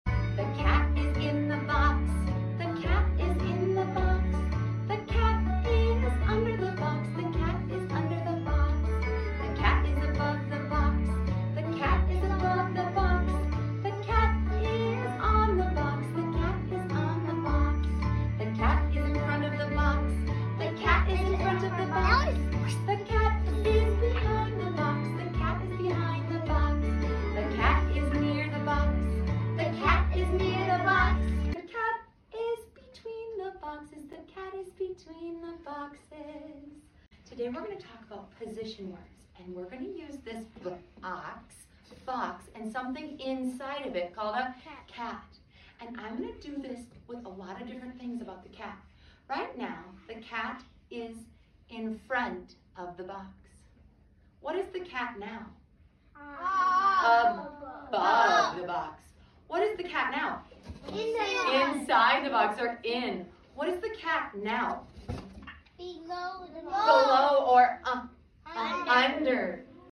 Learn English With Me through song!